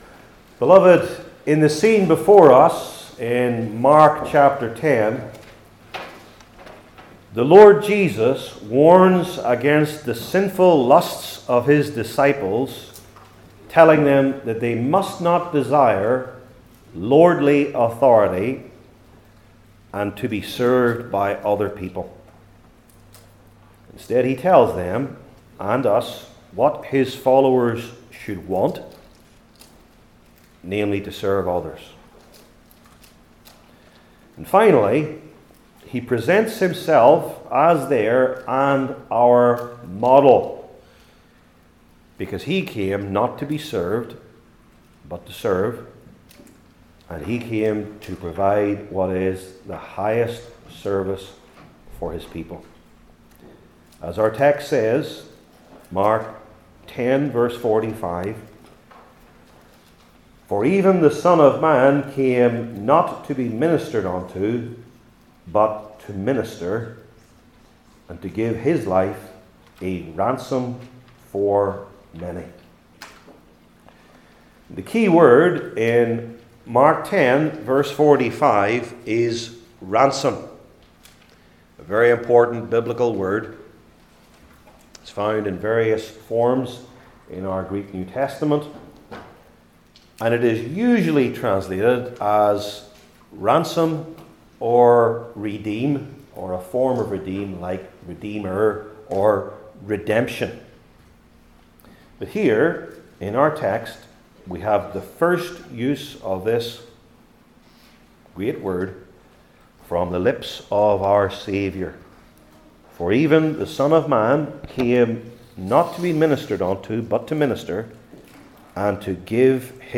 New Testament Individual Sermons I. From What?